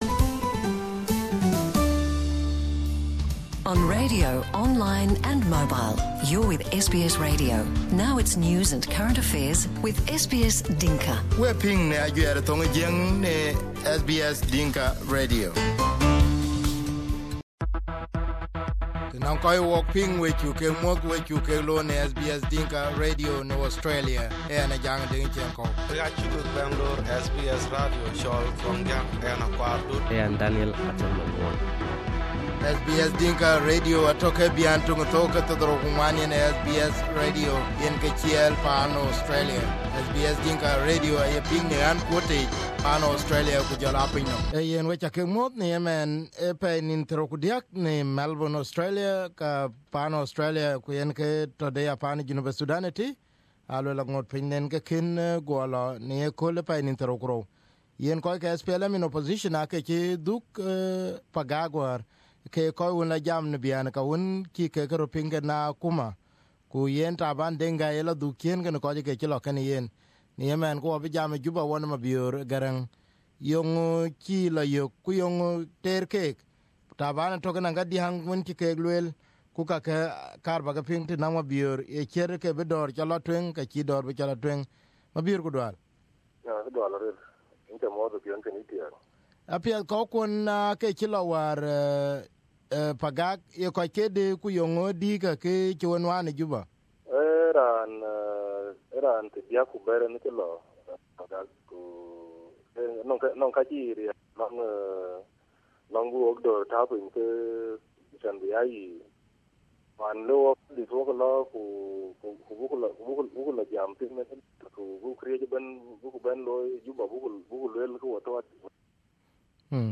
Few hours after the delegation return to Juba, we managed to speak with Mabior Garang who is the spokesperson and here is the interview on SBS Dinka Radio.